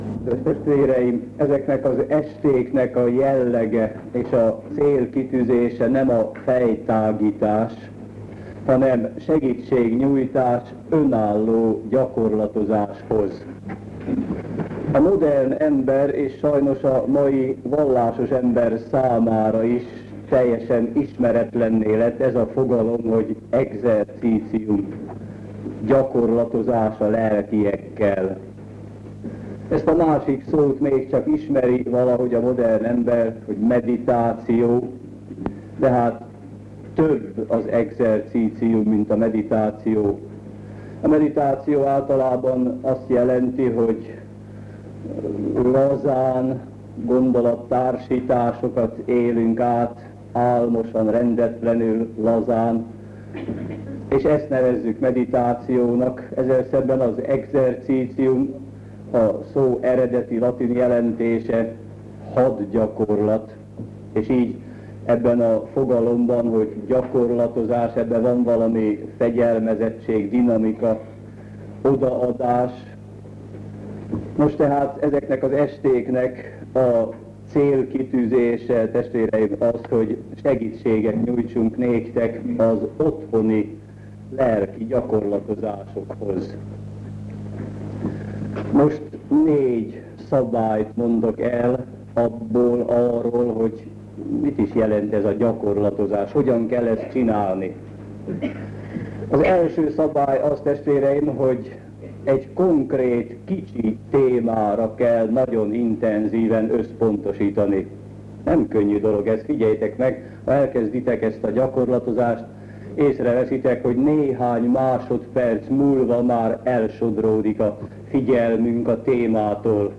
igehirdetései